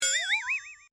Вы можете слушать онлайн и скачивать различные вопросительные интонации, загадочные мелодии и звуковые эффекты, создающие атмосферу тайны.
Знак вопроса